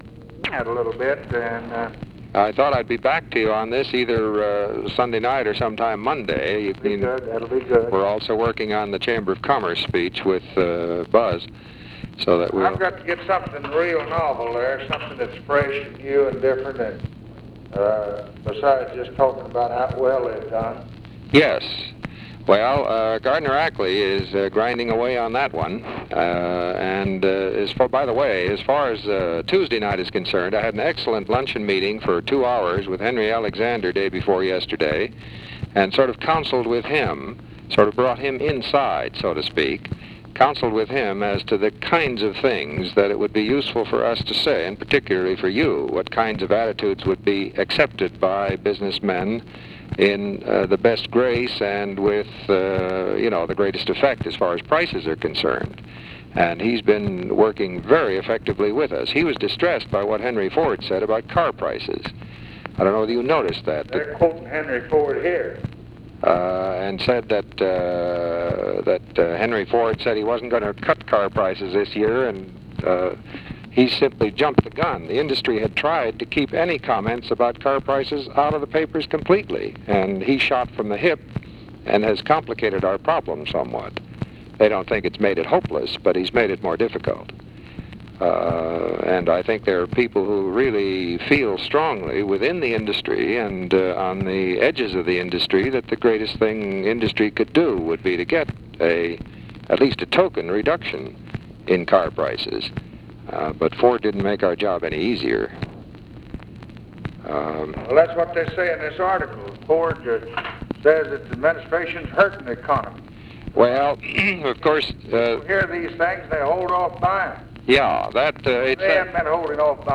Conversation with WALTER HELLER, April 23, 1964
Secret White House Tapes